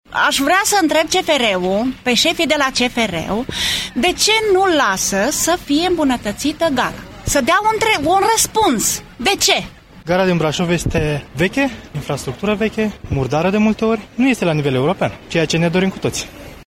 GARA-VOXURI.mp3